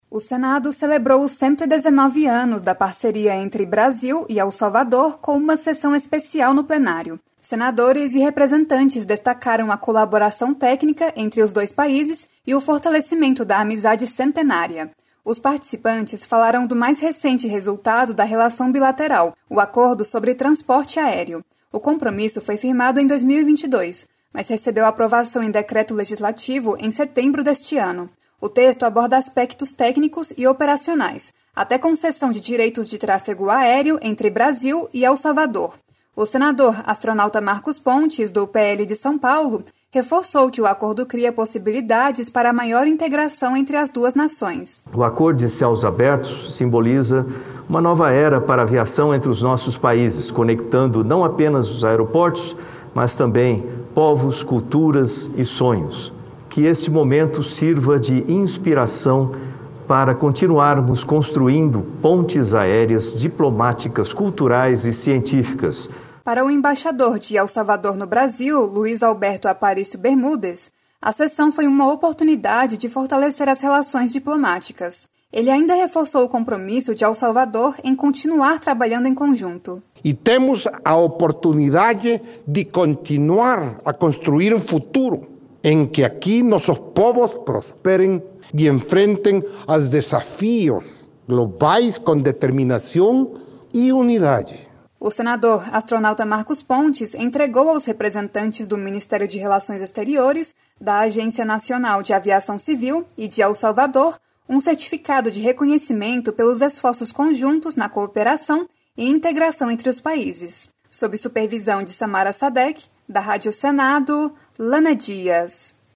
Sessão Especial
Uma sessão especial no Plenário do Senado, nesta quinta-feira (13), celebrou os 119 anos da parceria entre Brasil e El Salvador. Os participantes destacaram ações recentes que reforçaram a relação diplomática entre os países, como o acordo sobre transporte aéreo.